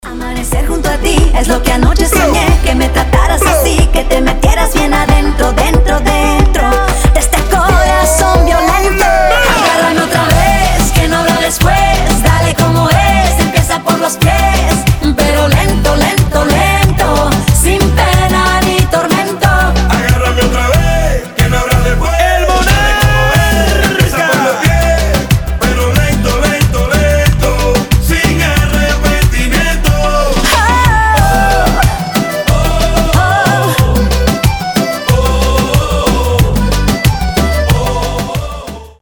• Качество: 320, Stereo
зажигательные
заводные
Reggaeton
Latin Pop